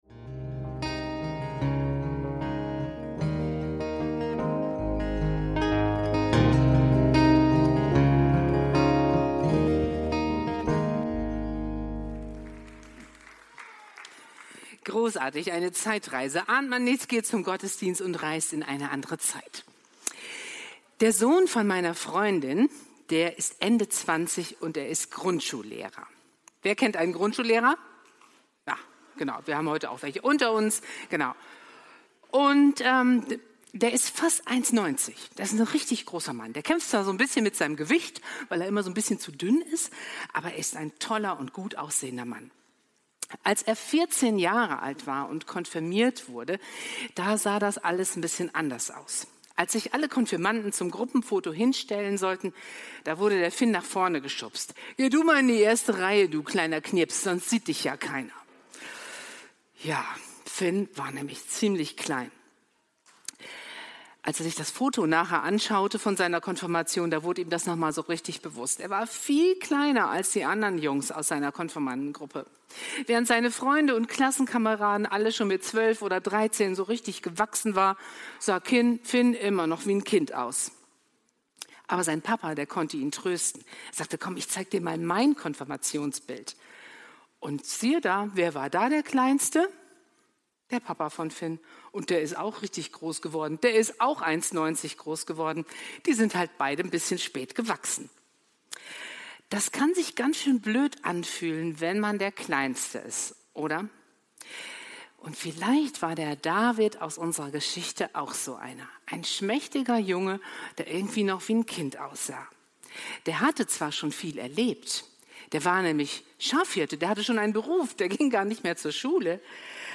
Klein gegen Groß – Predigt vom 24.08.2025